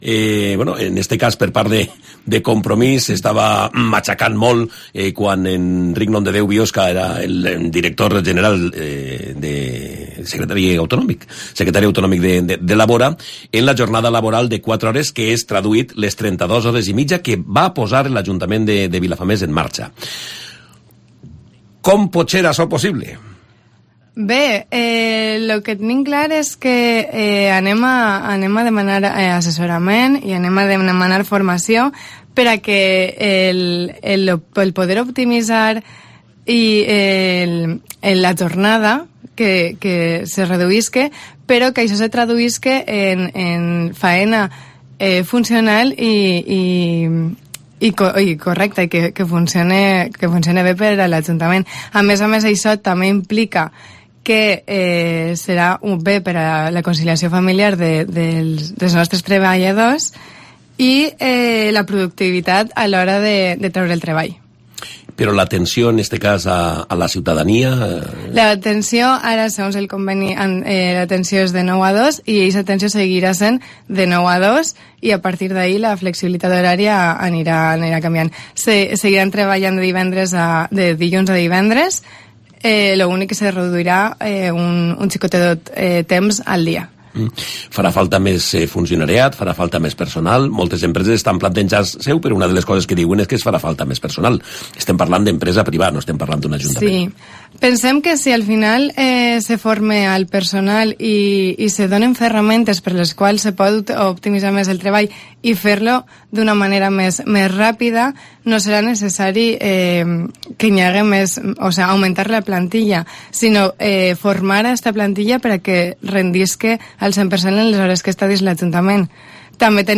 Andrada, de Compromís, se ha pasado por los estudios de COPE Castellón para hacer balance de los primeros 100 días de gobierno.